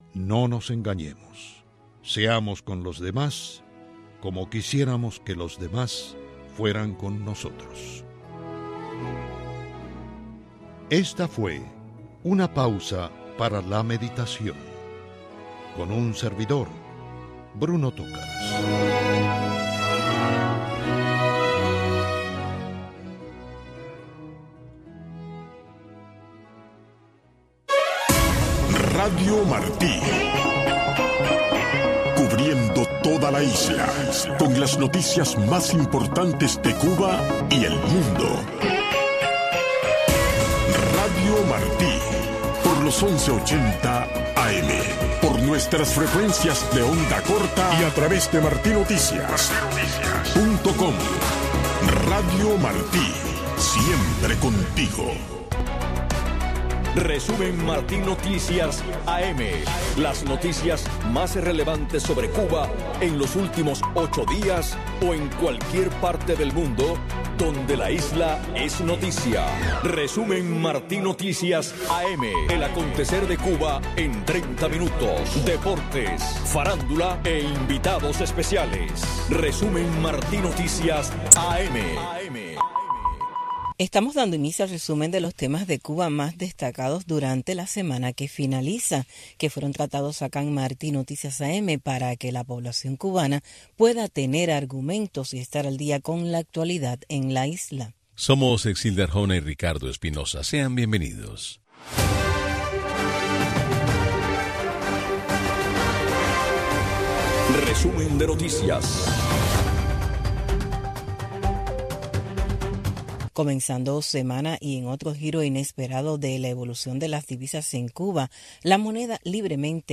Un resumen ágil y variado con las noticias más relevantes que han ocurrido en Cuba en los últimos 8 días o en cualquier parte del mundo donde un tema sobre la isla es noticia, tratados con invitados especiales. Media hora de información, deportes y farándula.